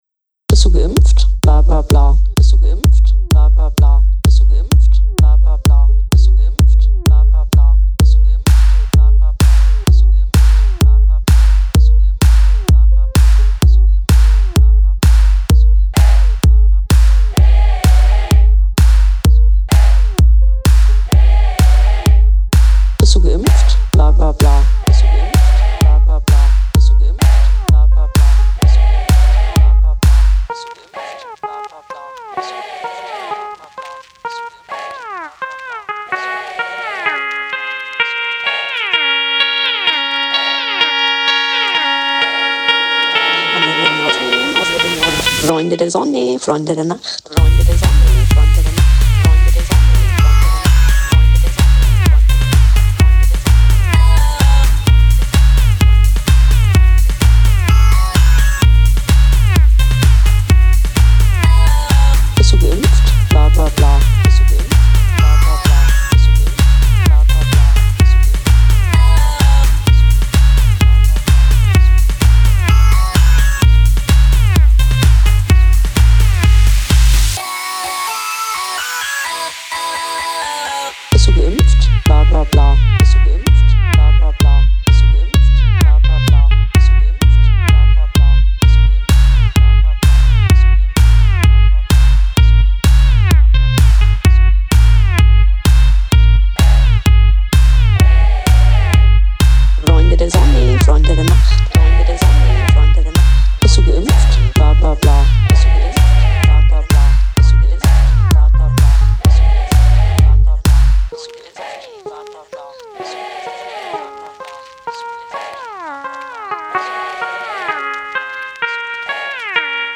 dance song
Pop, Dance, Electro, EDM
Gm